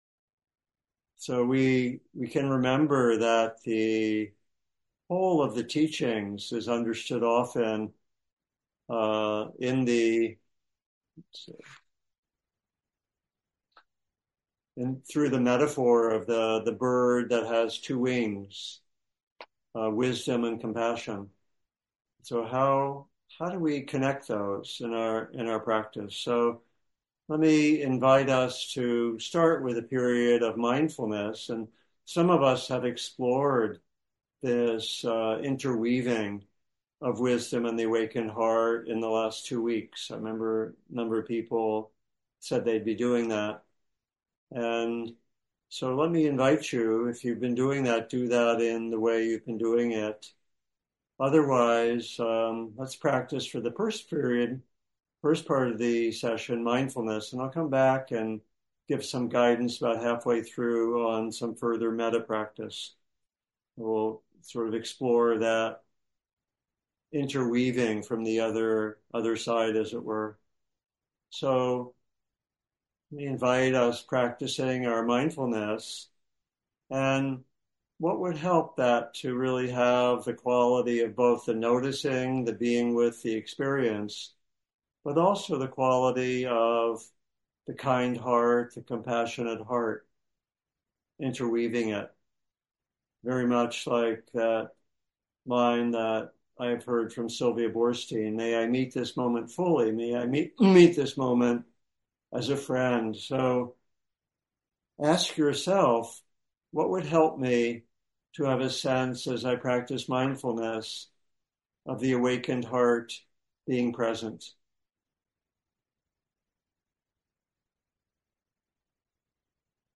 Buddhist Meditation Group